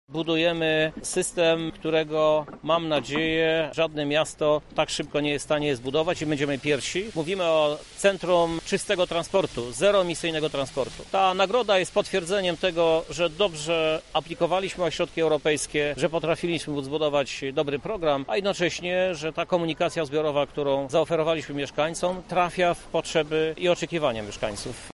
O znaczeniu „asa” dla lubelskich władz mówi prezydent miasta, Krzysztof Żuk: